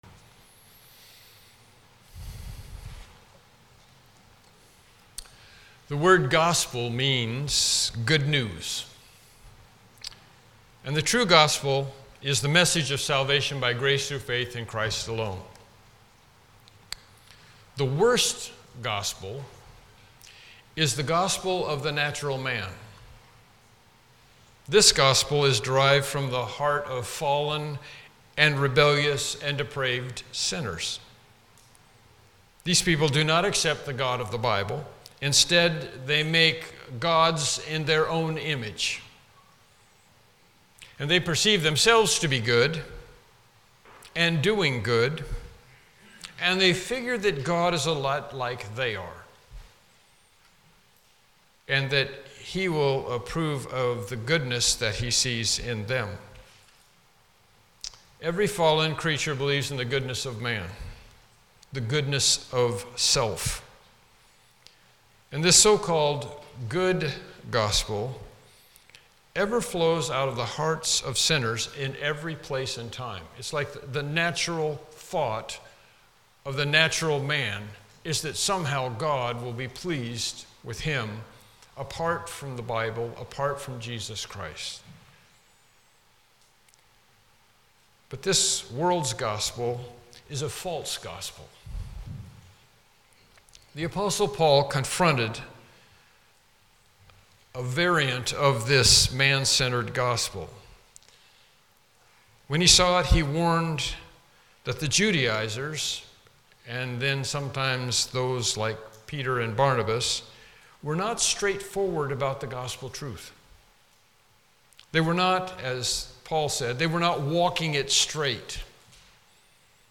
Galatians Passage: Galatians 2:15-18 Service Type: Morning Worship Service « Lesson 8